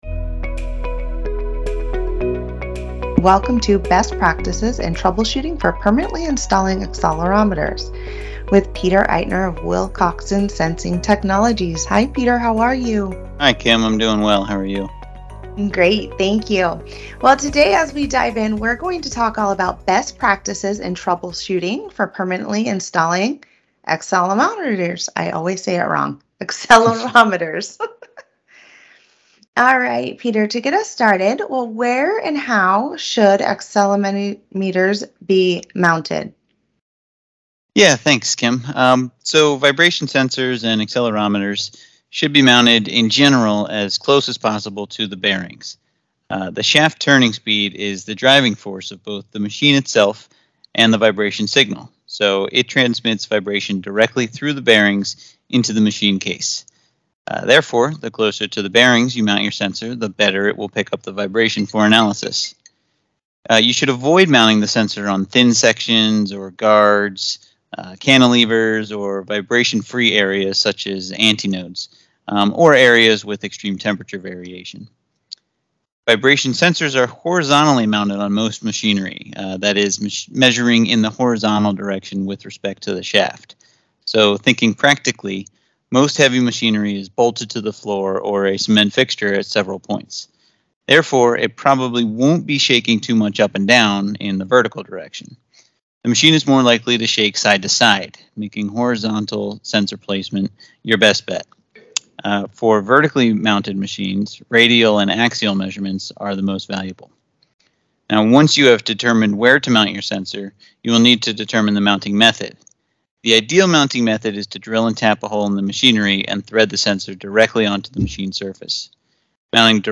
MOBIUS CONNECT Interviews